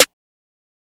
Snare (9).wav